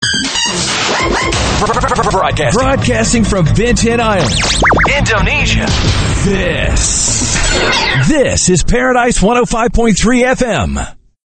RADIO IMAGING / TOP 40